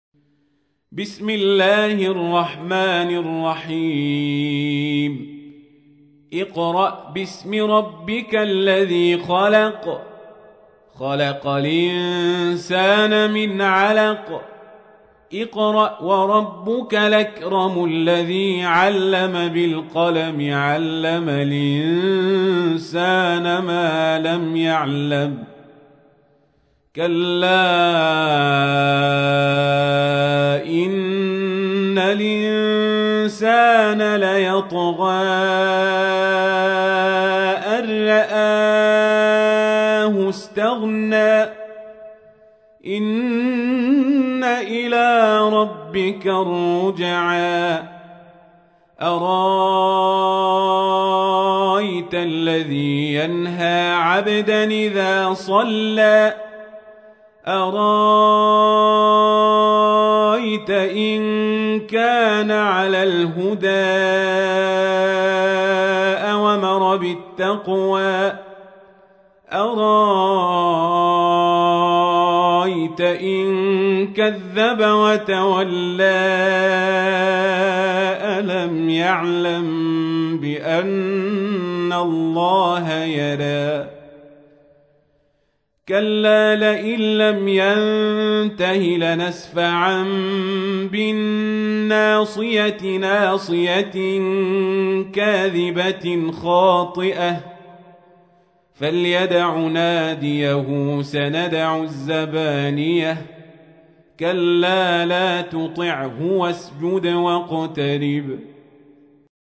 تحميل : 96. سورة العلق / القارئ القزابري / القرآن الكريم / موقع يا حسين